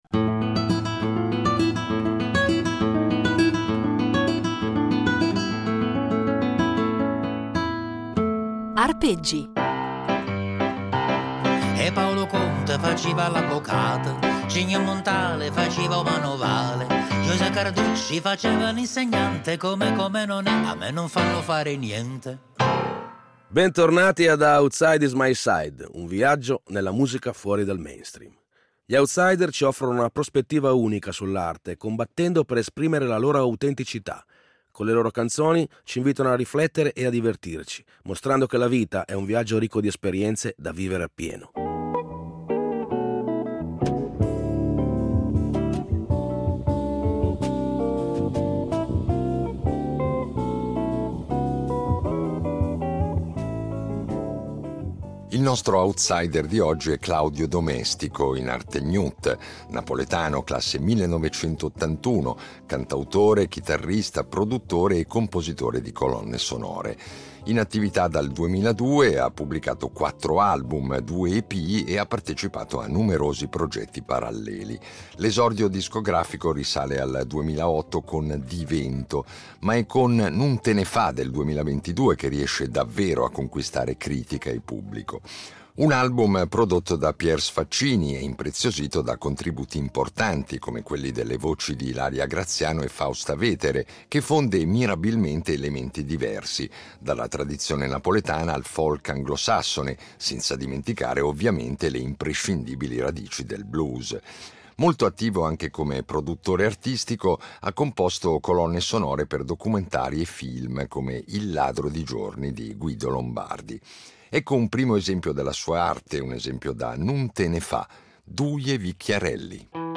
Sì, divertire, perché la musica d’autore è anche molto divertente e in queste 10 puntate ce ne accorgeremo, grazie alla disponibilità di dieci outsider o presunti tali, che ci offriranno dal vivo anche un assaggio della loro arte.